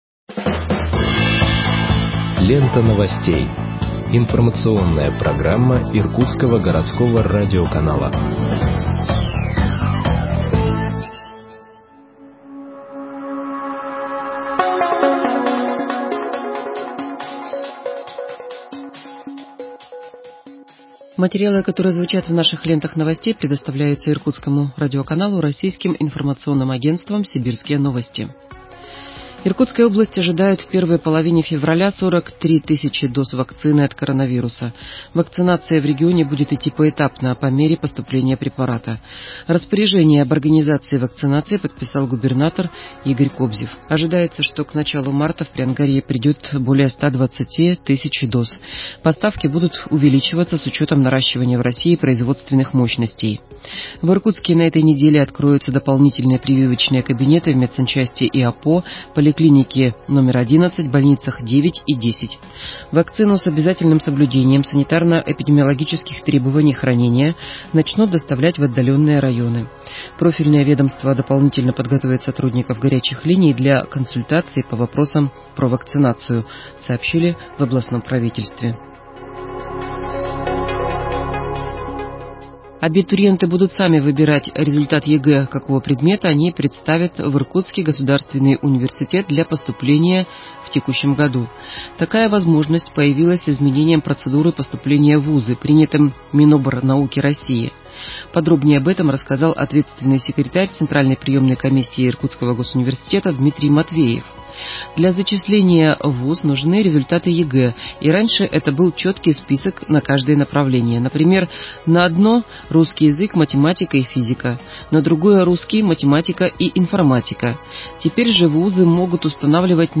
Выпуск новостей в подкастах газеты Иркутск от 20.01.2021 № 1